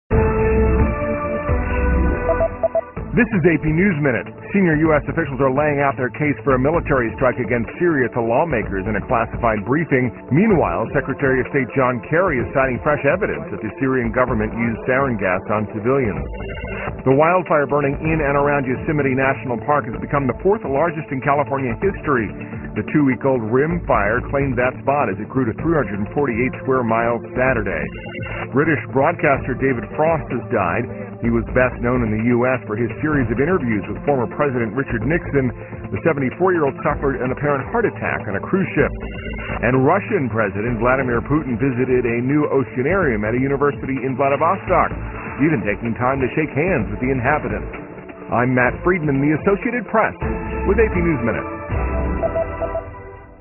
在线英语听力室美联社新闻一分钟 AP 2013-09-04的听力文件下载,美联社新闻一分钟2013,英语听力,英语新闻,英语MP3 由美联社编辑的一分钟国际电视新闻，报道每天发生的重大国际事件。电视新闻片长一分钟，一般包括五个小段，简明扼要，语言规范，便于大家快速了解世界大事。